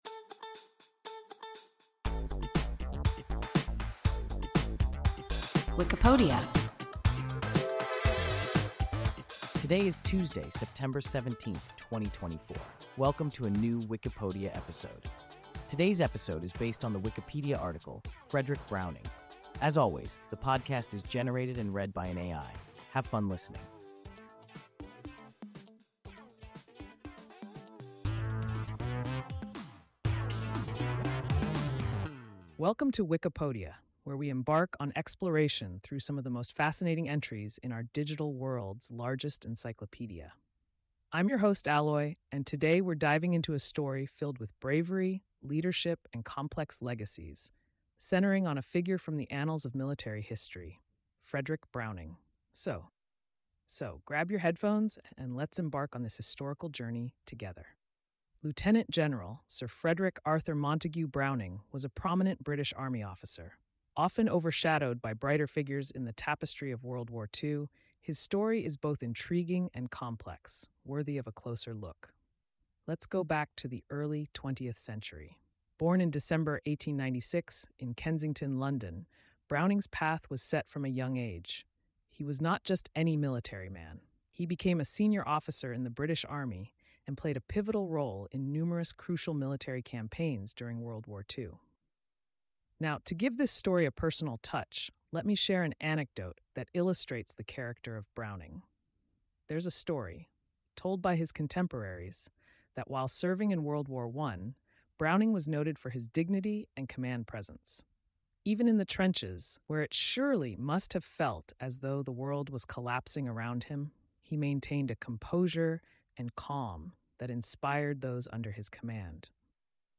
Frederick Browning – WIKIPODIA – ein KI Podcast